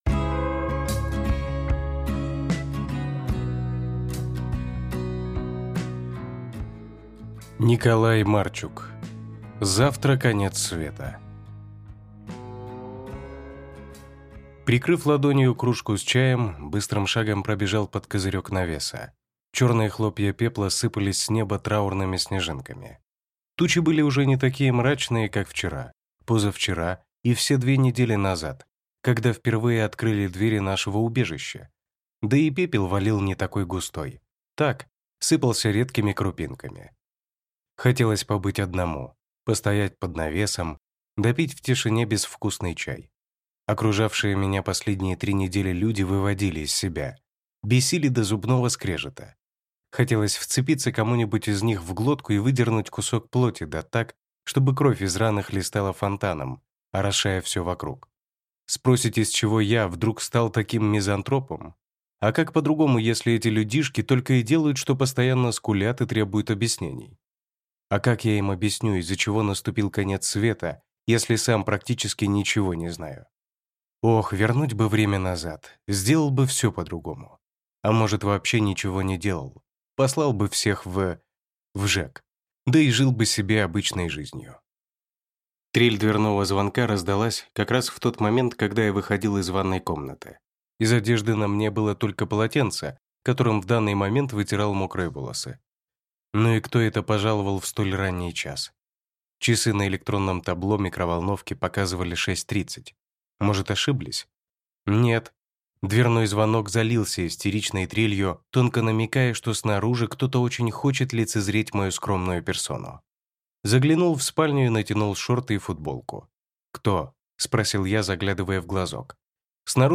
Aудиокнига Завтра Конец Света